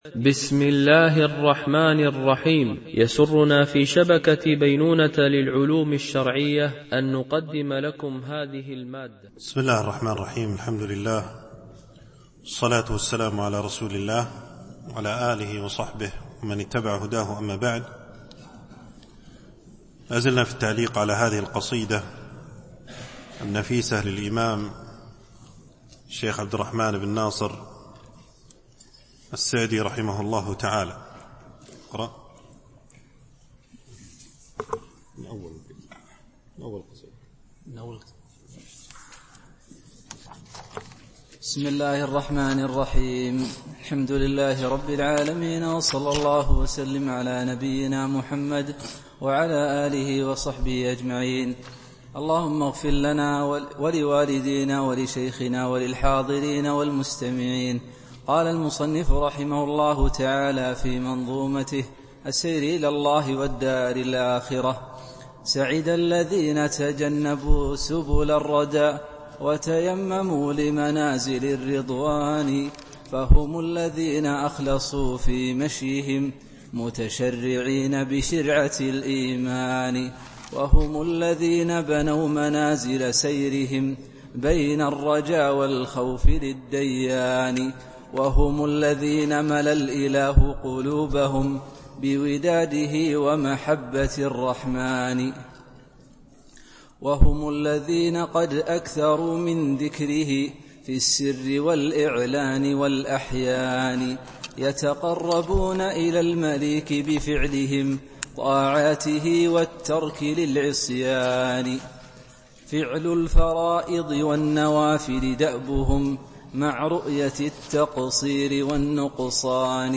دورة علمية شرعية
بمسجد عائشة أم المؤمنين - دبي (القوز 4)